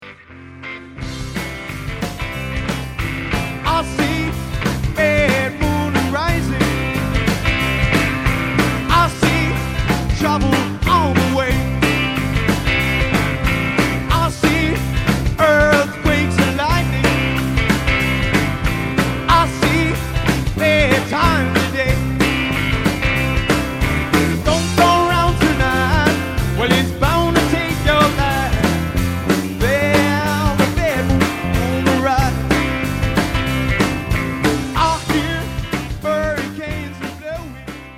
Pro höör på MP3 betona från live konsertta 1999: